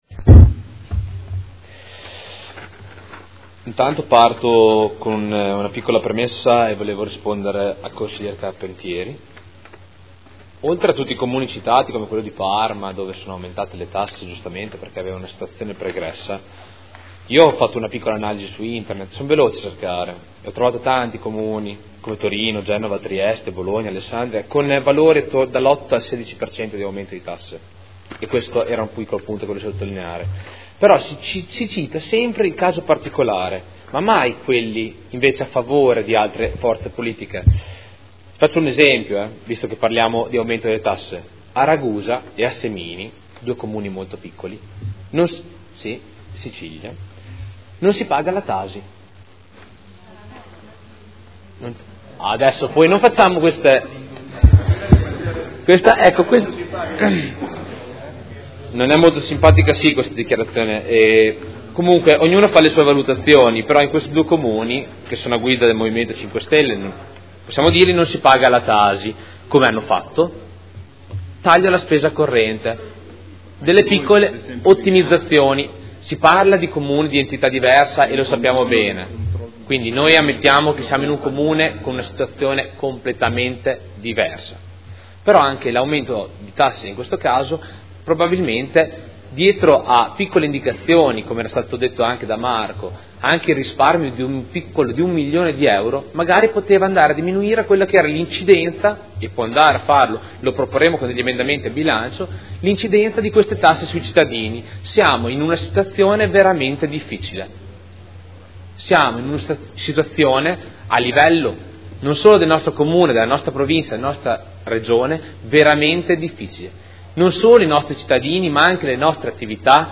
Seduta del 29/01/2015. Documento Unico di Programmazione 2015/2019 – Sezione strategica. Dibattito